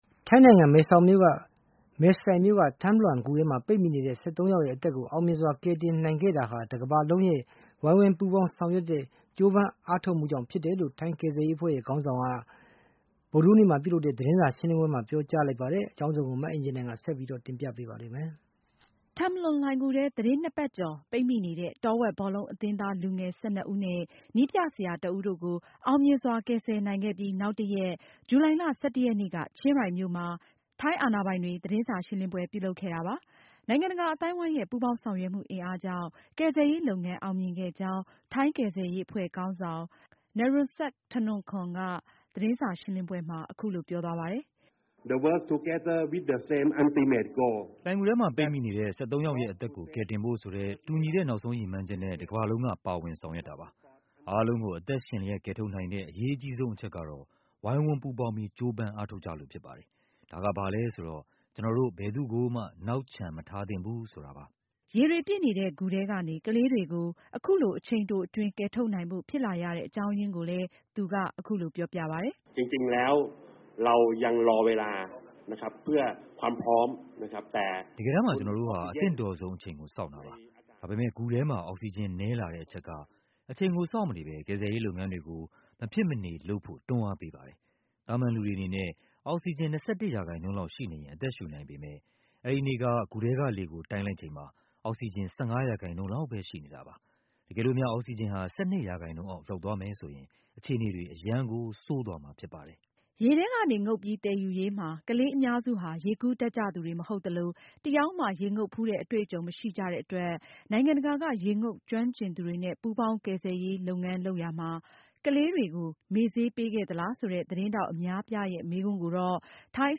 ရေတွေပြည့်နေတဲ့ ဂူထဲကနေ ကလေးတွေကို အခုလို အချိန်တိုအတွင်း ကယ်ထုတ်နိုင်ဖို့ ဖြစ်လာရတဲ့အကြောင်းအရင်းကိုလည်း သူက အခုလိုပြောပြပါတယ်။